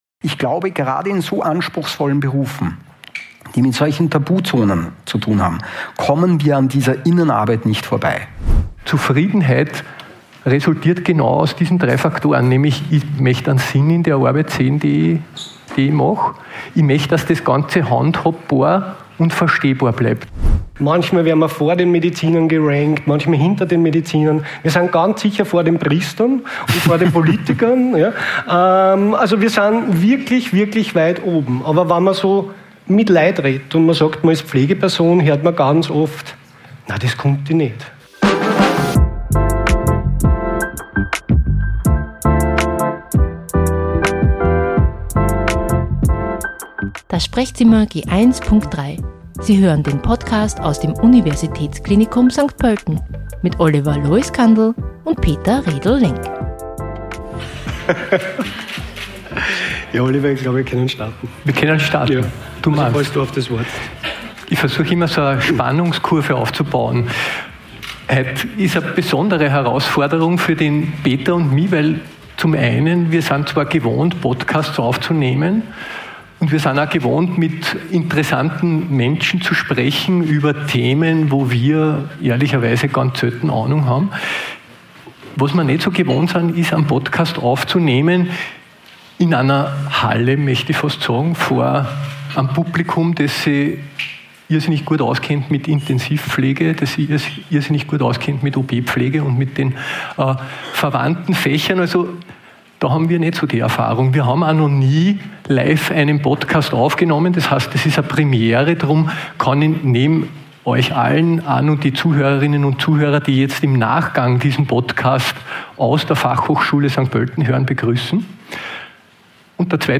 #47 LIVE-Podcast: Vitalität in der Pflege ~ Das Sprechzimmer G1.3: Podcast aus dem Universitätsklinikum St. Pölten Podcast